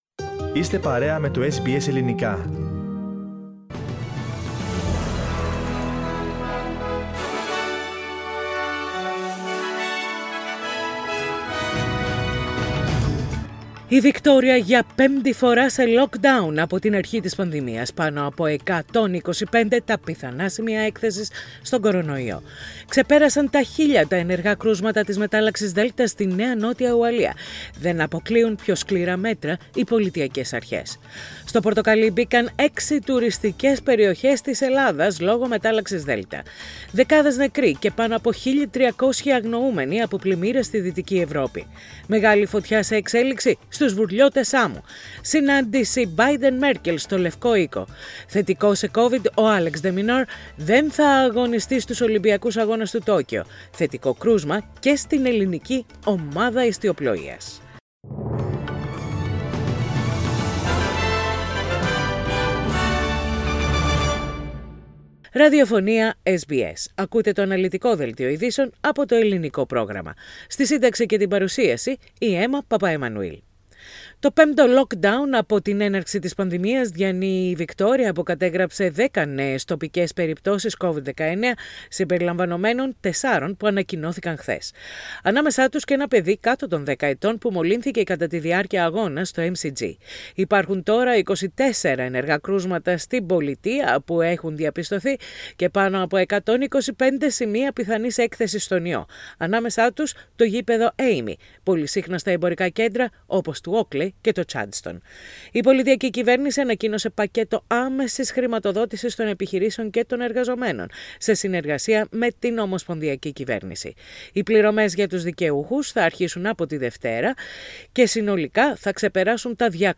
Ειδήσεις στα Ελληνικά - Παρασκευή 16.7.21